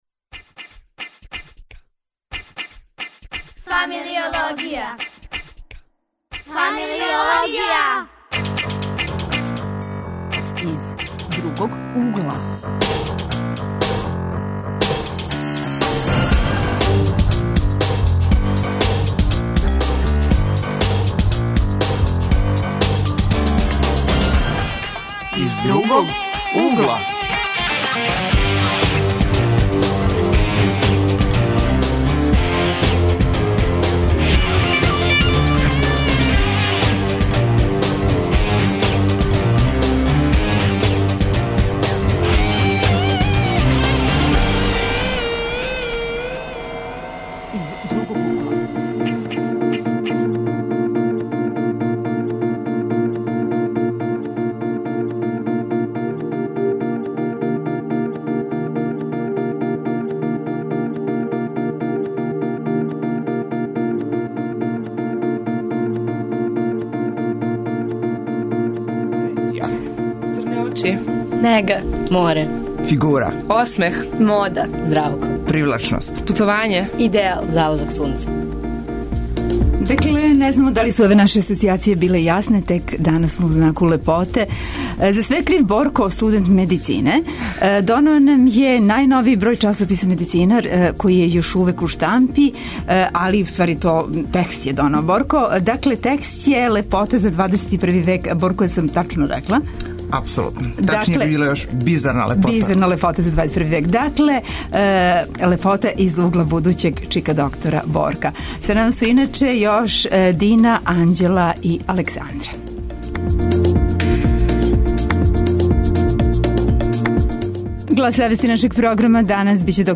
Емисија Из другог угла - данас је у знаку лепоте. Гости ће нам бити млади који се у слободно време баве глумом, спортом, новинарством.